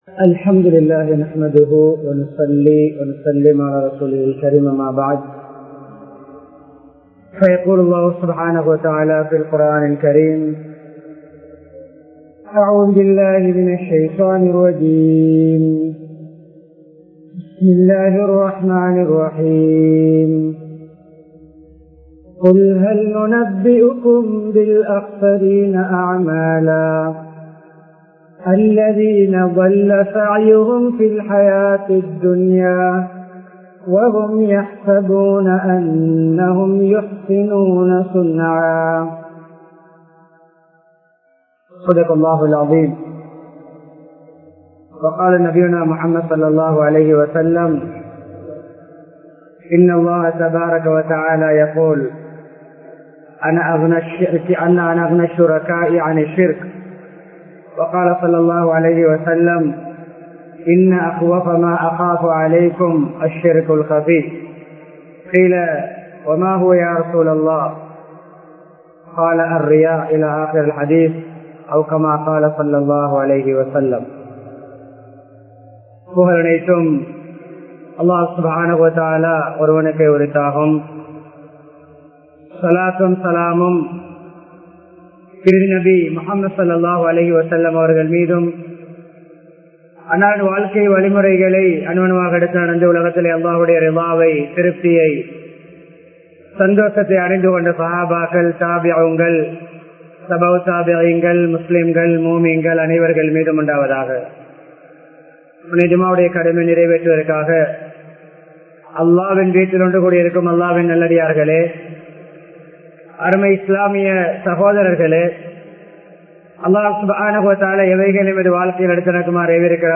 நற்செயல்களை அழிக்கும் செயல்கள் | Audio Bayans | All Ceylon Muslim Youth Community | Addalaichenai